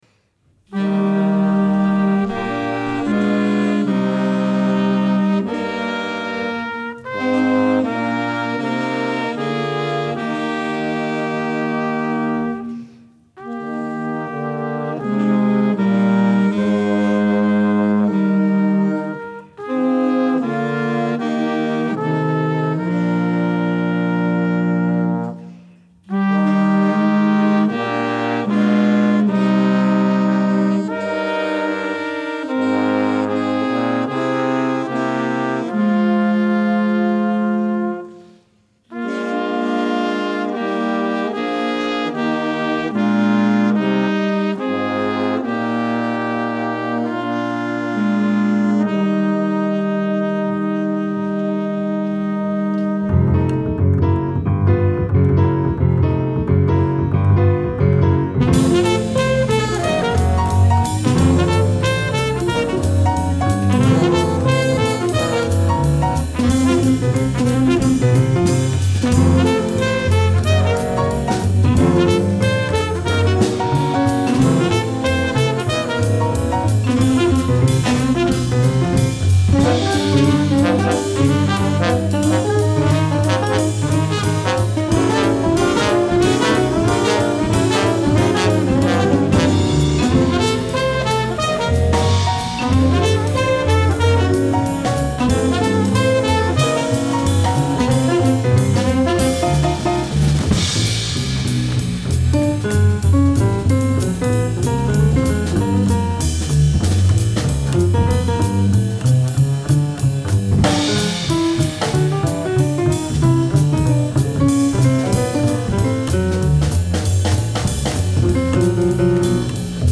- L’Alhambra – Rue de la Rôtisserie 10 – 1204 Genève
saxophone soprano, clarinette basse
saxophone alto
saxophones ténor, soprano et basse
trompette, tuba
trombone, tuba
guitare et guitare basse
contrebasse
batterie, vibraphone